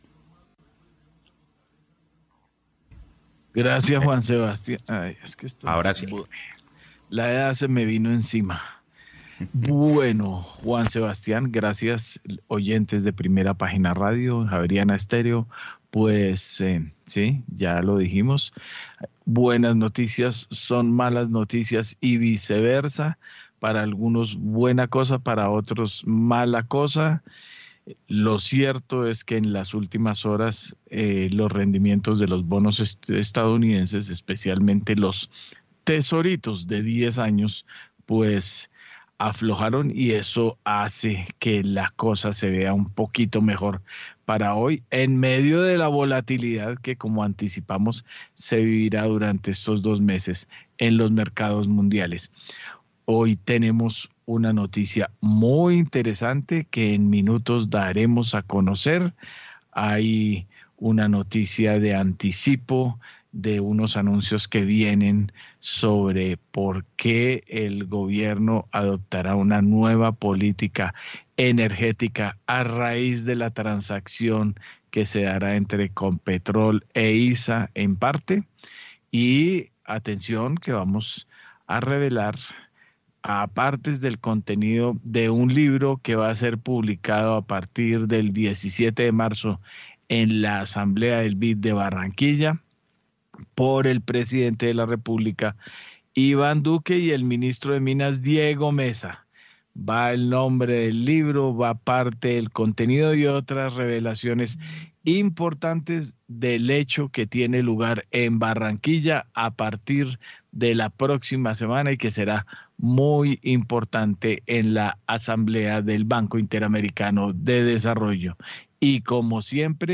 hizo parte del panel de expertos en el espacio Primera Página, de la emisora Javeriana Estéreo, en donde hablaron de los diferentes movimientos financieros que se han dado en Estados Unidos durante el último año de pandemia, y las ayudas económicas que ha brindado el Gobierno Estadounidense, que tendrán repercusión en la inflación local.